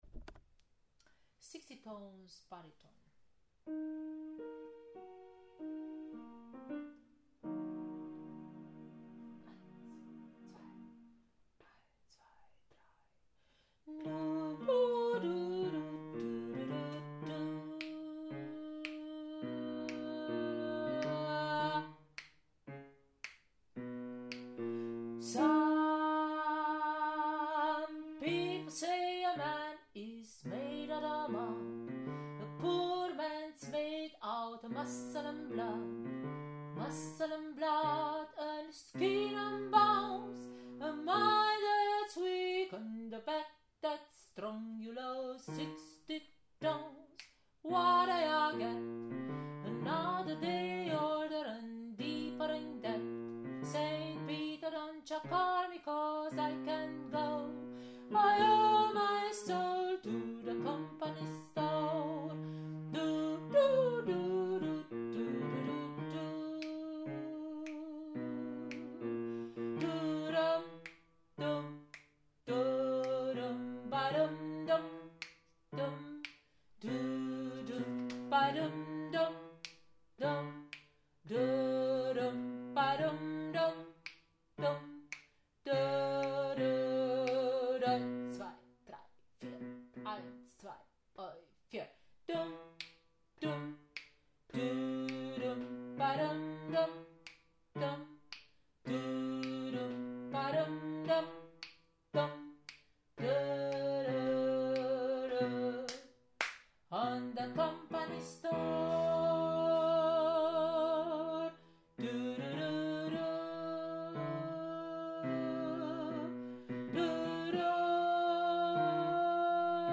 Von 19:15 bis 20:00 Uhr proben die NoNames, unser kleines, aber feines, Männerensemble, am gleichen Ort.
Sixteen-Tons-Bariton.mp3